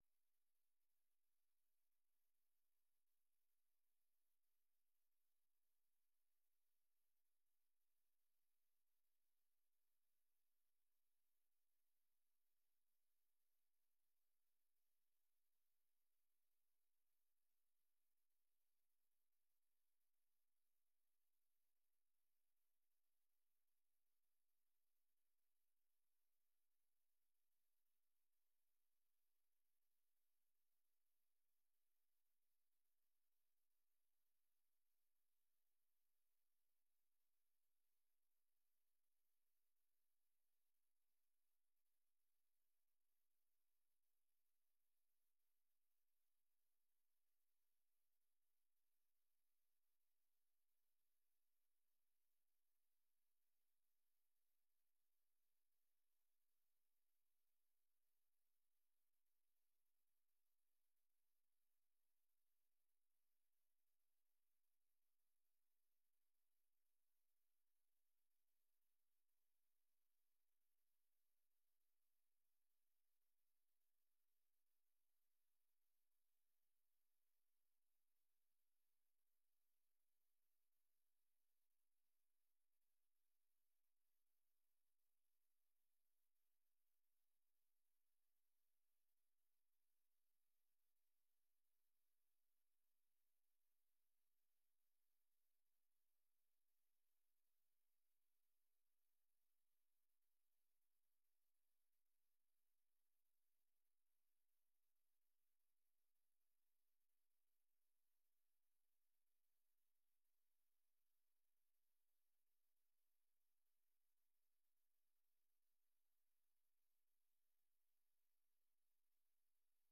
Locatie: Kamer 63